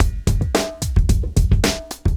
110LOOP B7-L.wav